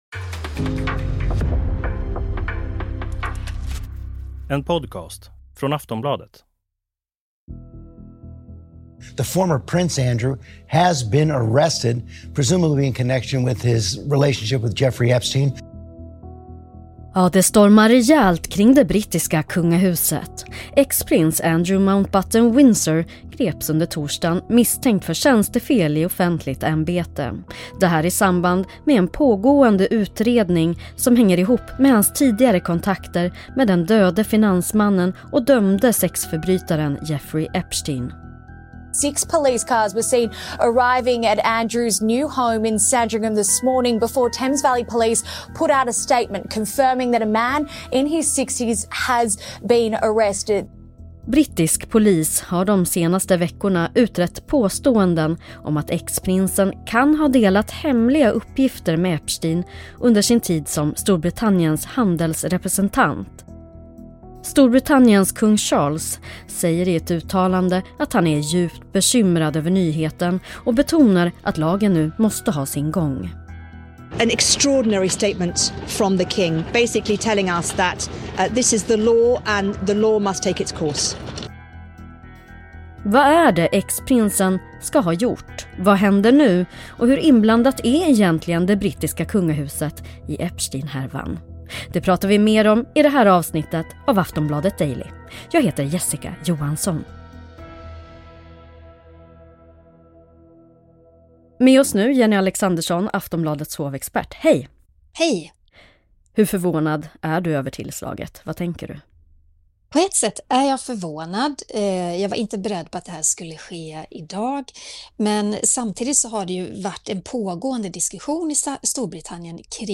Klipp från: ABC News, 9News, BBC.